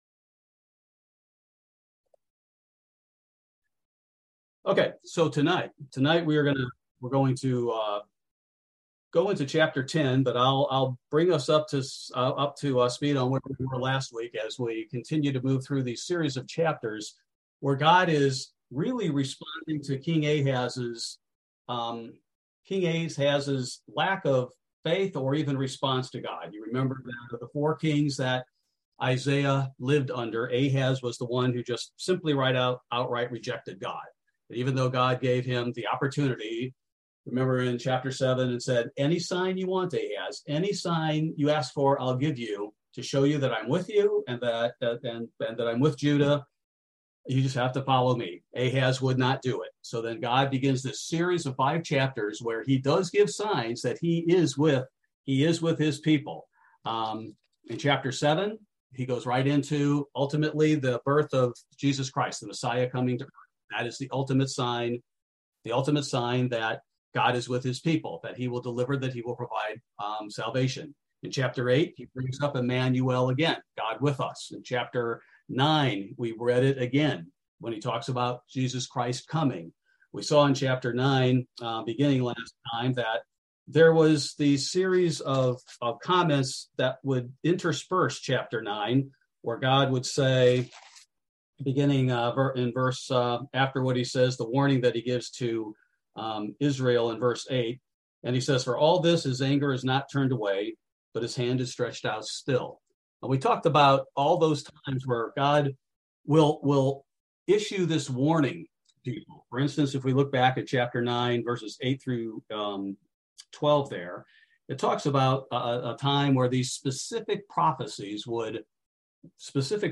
Bible Study: August 31, 2022